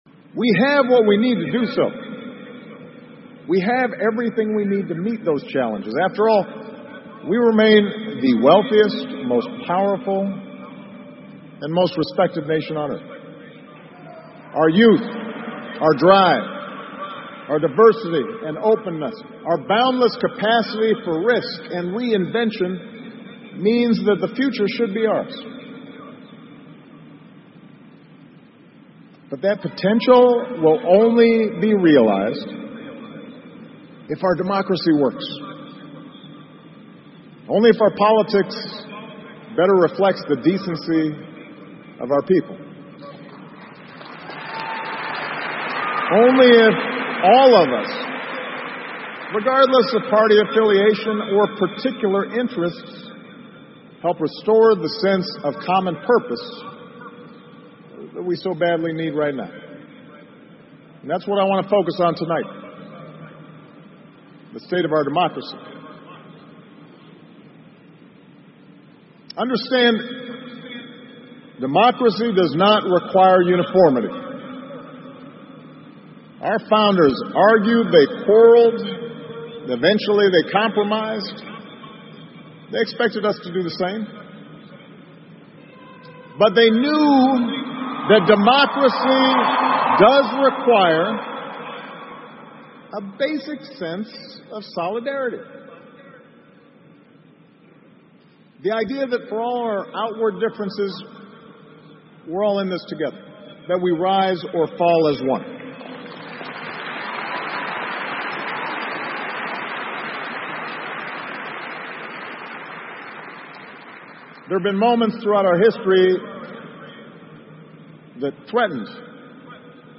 奥巴马每周电视讲话：美国总统奥巴马告别演讲(5) 听力文件下载—在线英语听力室